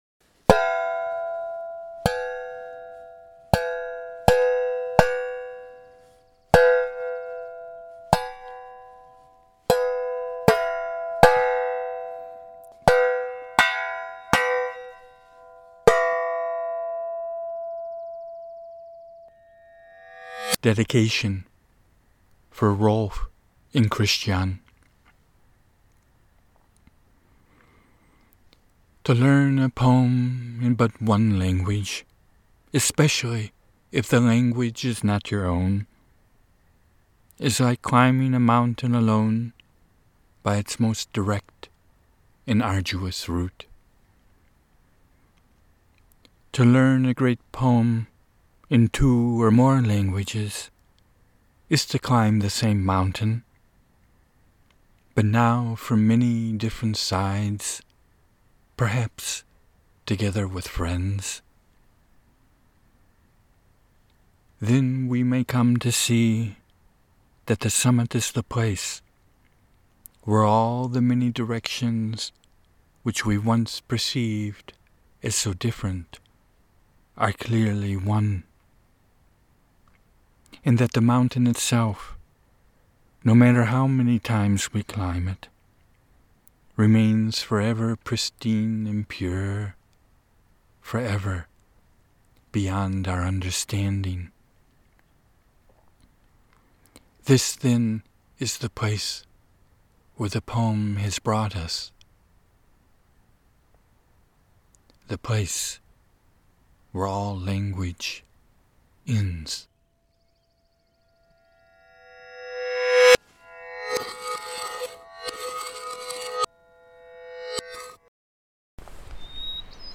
new RILKE translations: of 20 poems & 12 wilderness soundscape interpolations, featuring among others Hermit Varied, and Swainson Thrushes, water sounds, coyote and Western Toad choruses